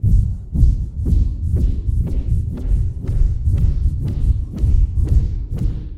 Звуки вращения
На этой странице собраны разнообразные звуки вращения: от легкого шелеста крутящихся лопастей до мощного гула промышленных механизмов.
Вращающийся сфероид